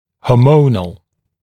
[hɔː’məunl][хо:’моунл]гормональный, гормонный